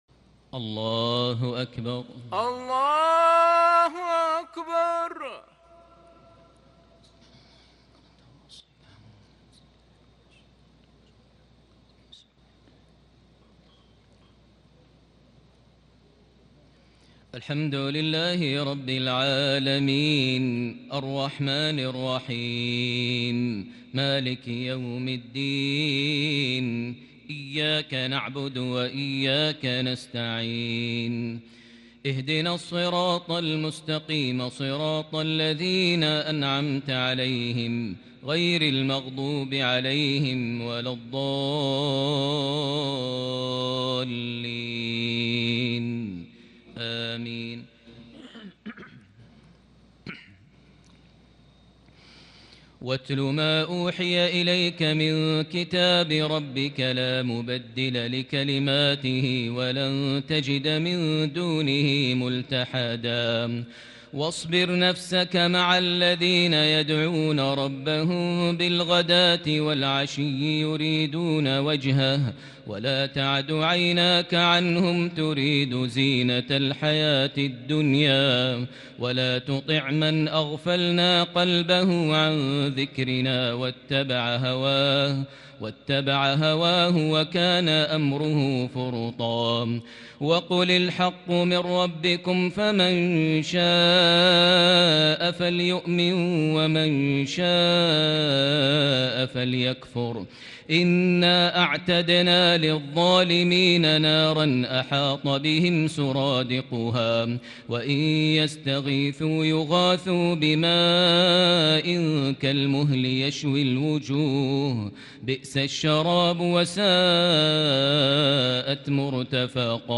تراويح ليلة 20 رمضان 1441هـ من سورة الكهف (27-82) Taraweeh 20 th night Ramadan 1441H Surah Al-Kahf > تراويح الحرم المكي عام 1441 🕋 > التراويح - تلاوات الحرمين